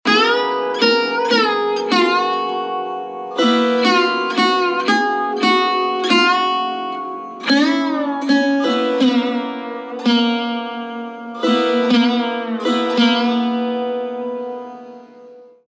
Raga
Shudh Sarang (Avaroha)